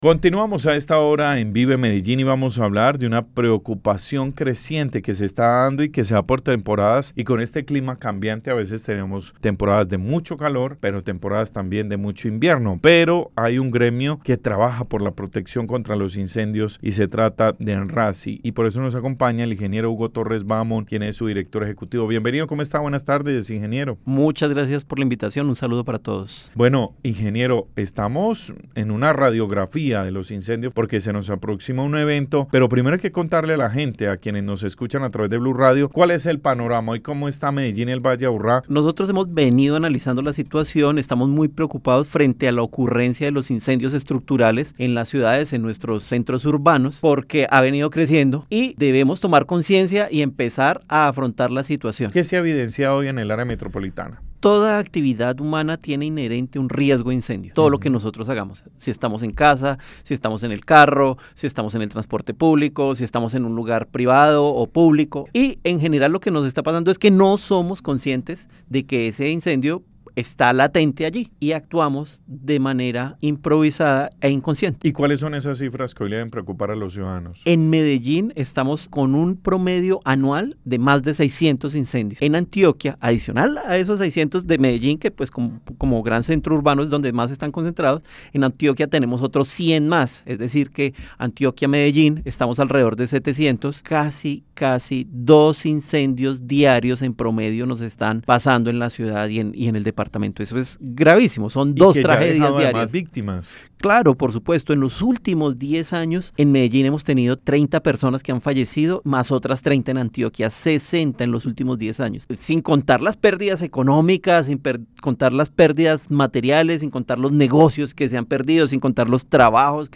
Entrevista-Blu-Radio-Incendios-Medellin-Antioquia-23-sep.mp3